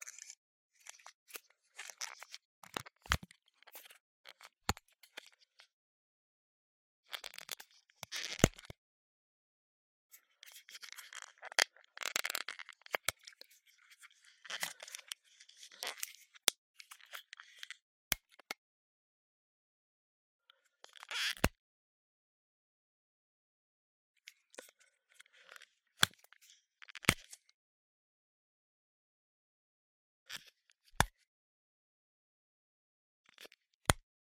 На этой странице собраны реалистичные звуки выстрелов из рогатки разными снарядами: от камней до металлических шариков.
Звук патрона в рогатке, точнее в резинку